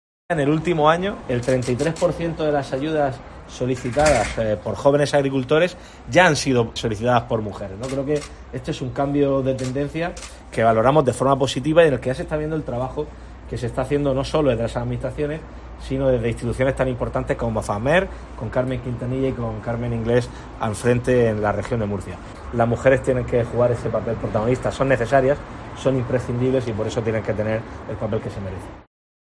Fernando López Miras, presidente de la Región de Murcia
Durante la inauguración de la jornada ‘Corresponsabilidad en el medio rural’, organizada por la Asociación de Familias y Mujeres del Medio Rural (Afammer) con motivo del Día Internacional de las Mujeres Rurales, celebrado ayer, 15 de octubre, López Miras puso en valor la capacidad de emprendimiento de la mujer en el ámbito agrícola y ganadero, y sus efectos positivos en el medio rural.